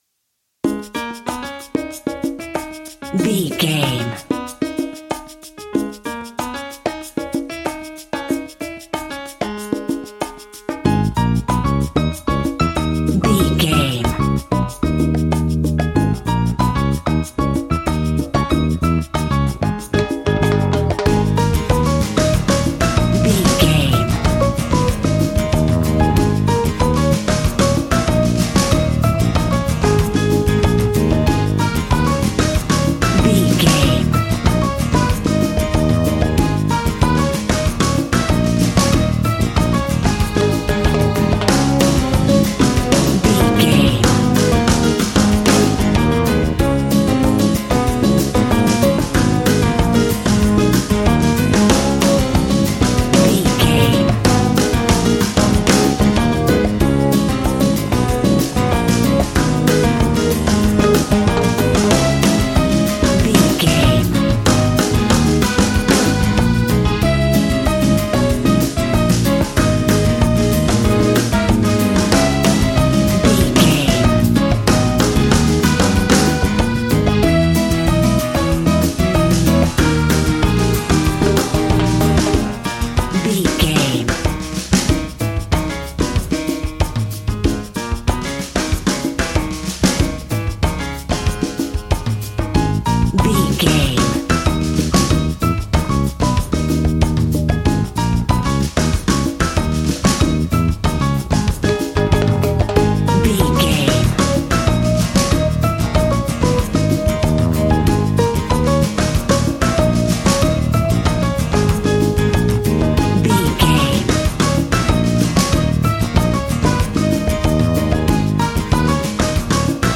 Aeolian/Minor
A♭
funky
energetic
romantic
percussion
electric guitar
acoustic guitar